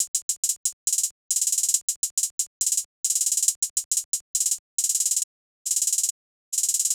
HiHat (39).wav